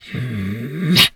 pgs/Assets/Audio/Animal_Impersonations/wolf_2_growl_04.wav at master
wolf_2_growl_04.wav